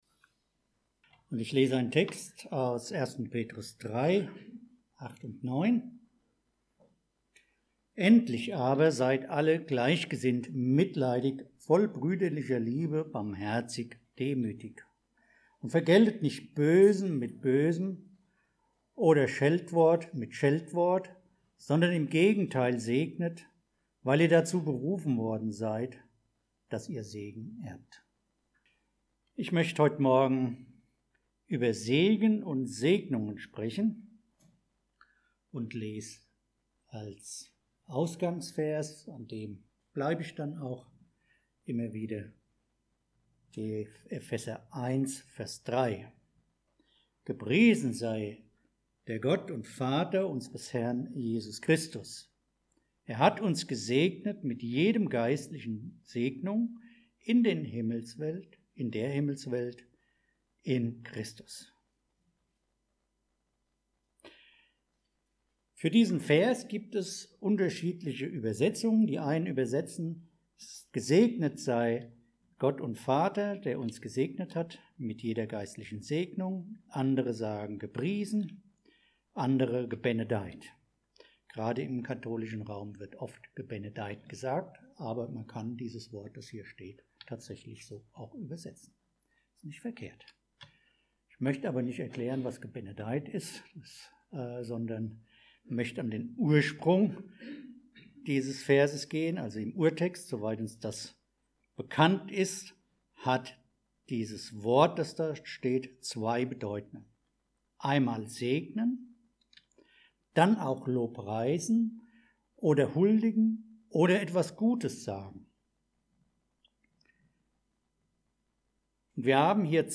1. Thessalonicher 5, 6-9 Download file Veröffentlicht unter Predigt Wunder der Gnade Veröffentlicht am 11.